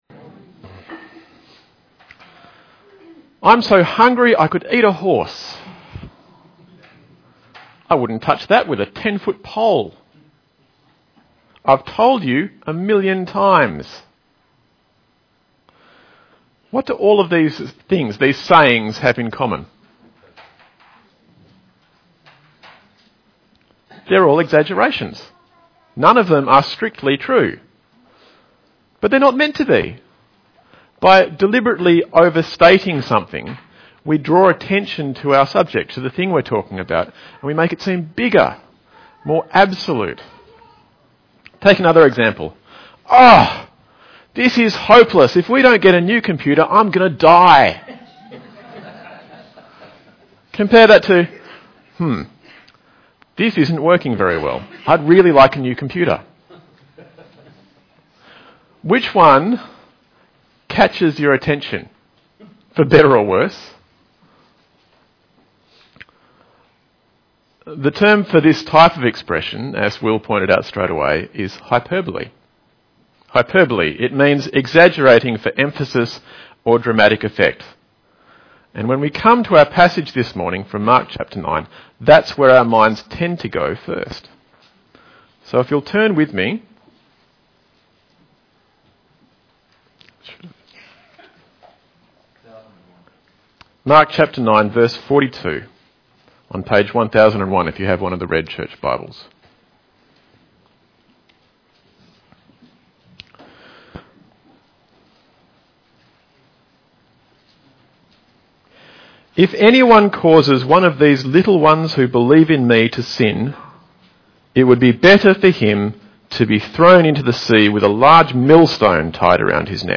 mark 9:42-50 Service Type: Sunday AM Bible Text